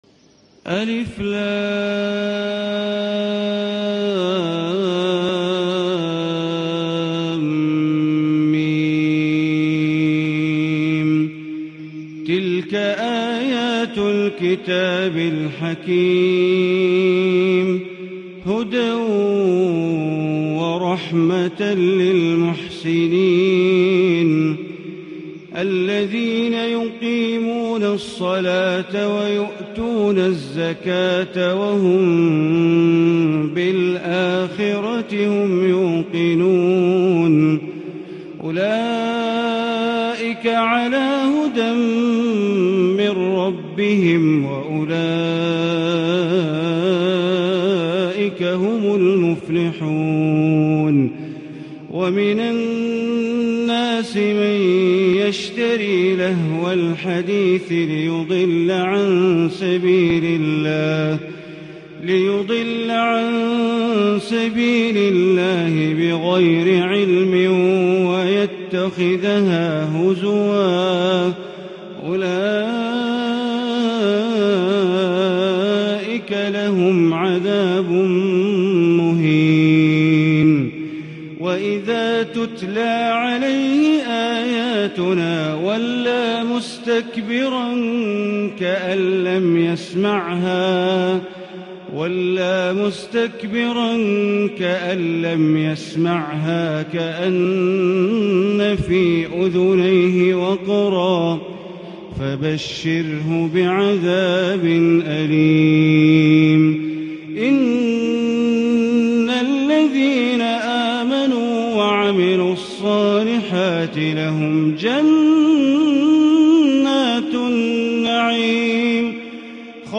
سورة لقمان > مصحف الحرم المكي > المصحف - تلاوات بندر بليلة